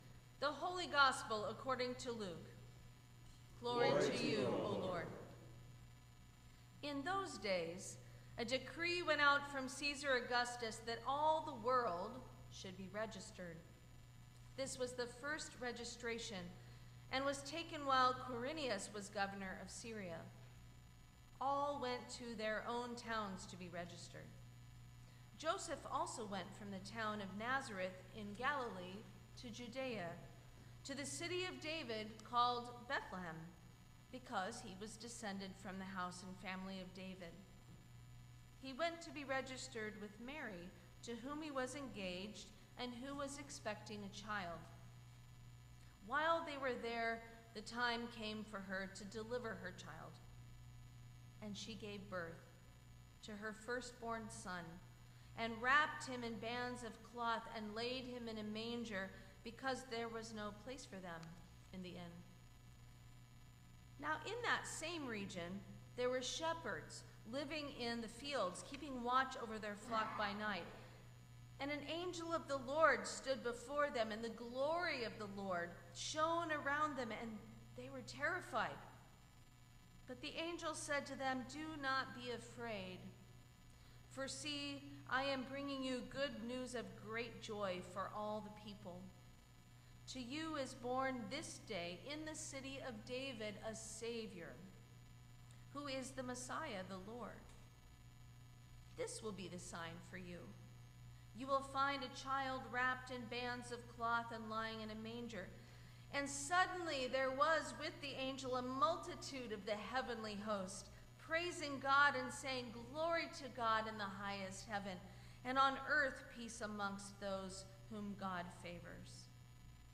Sermon for Christmas Eve 2025